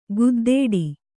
♪ guddāḍu